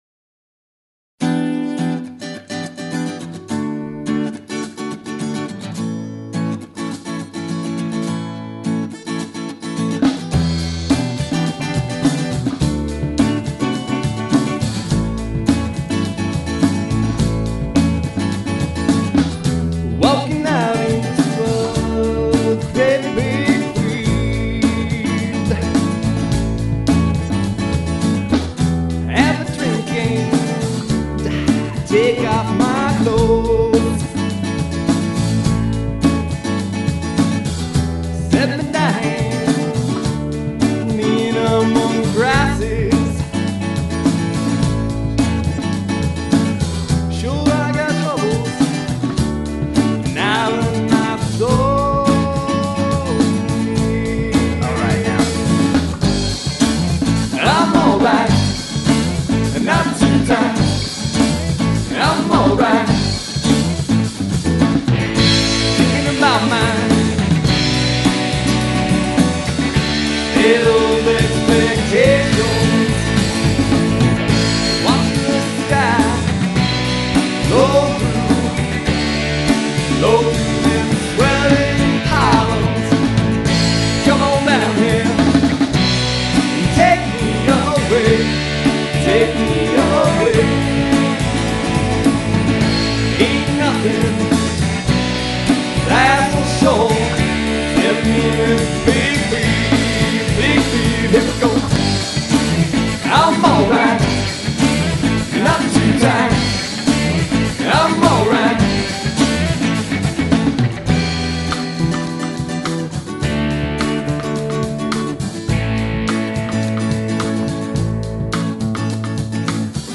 His baritone vocals range from haunting to passionate.